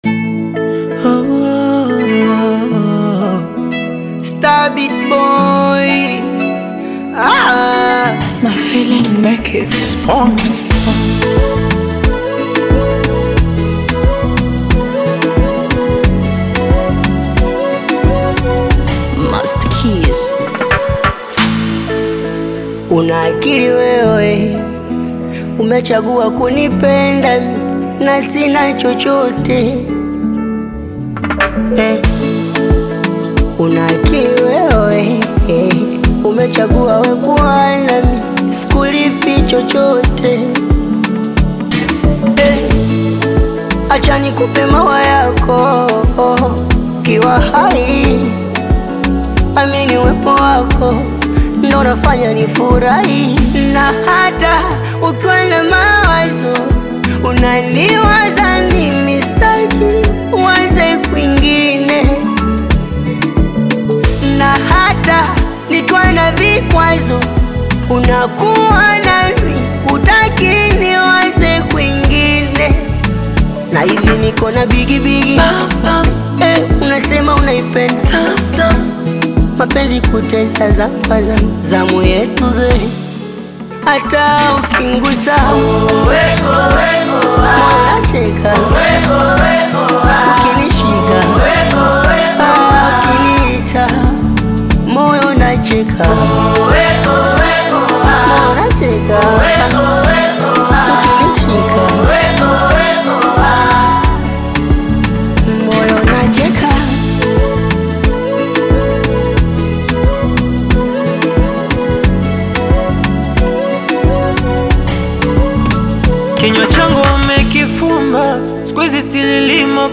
Bongo Flava song